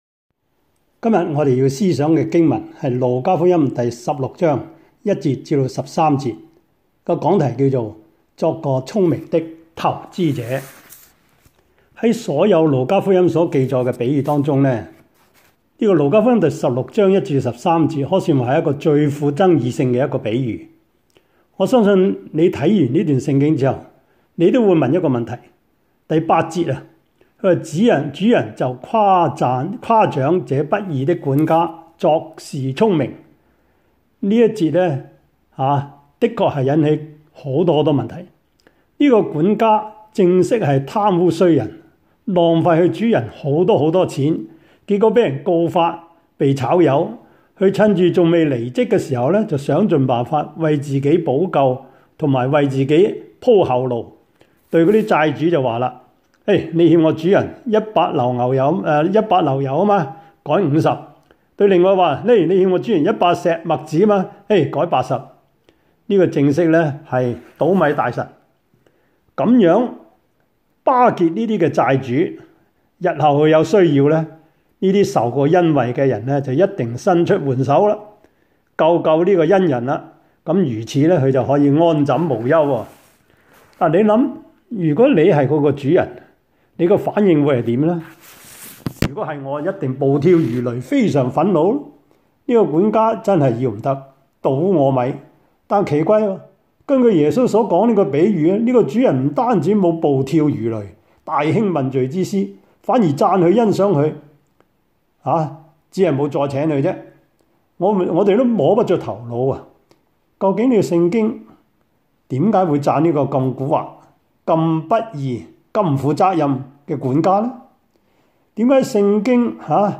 Series: 2020 主日崇拜